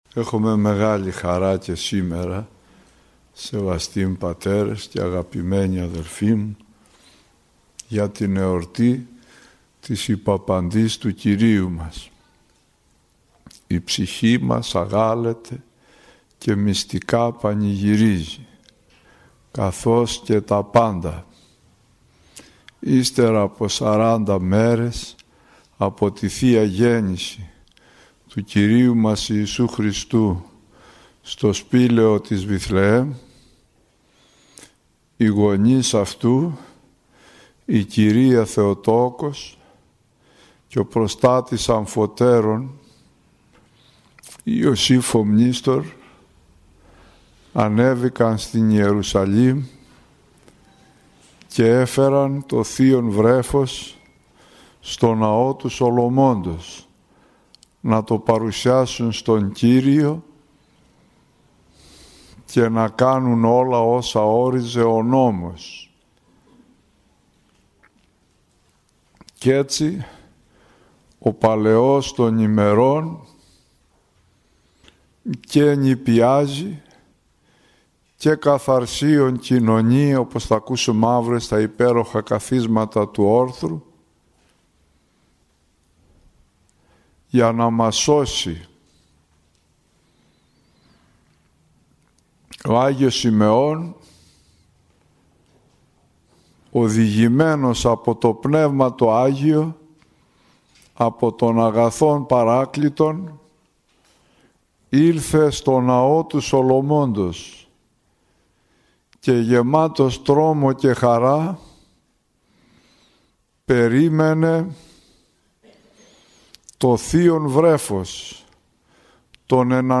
Ακολούθως παραθέτουμε ηχογραφημένη ομιλία του πανοσιολογιωτάτου Αρχιμανδρίτου